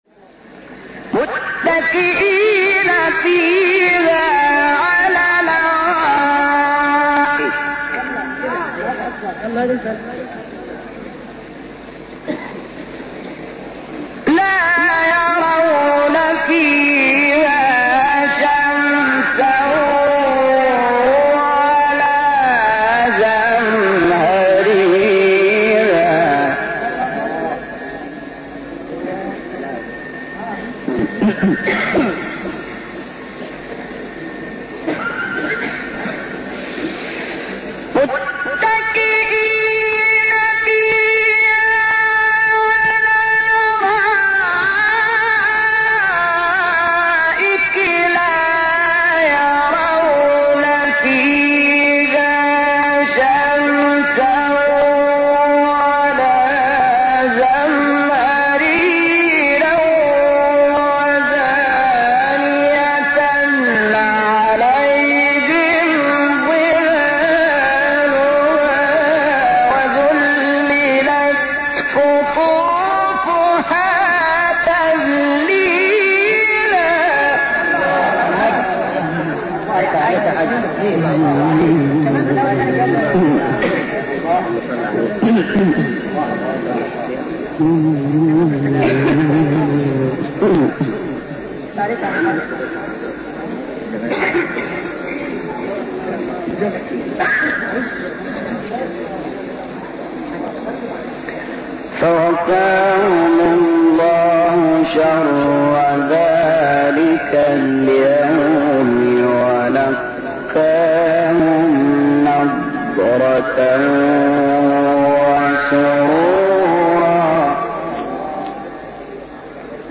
تلاوت آیه 11-14 سوره انسان جوانی استاد شحات | نغمات قرآن | دانلود تلاوت قرآن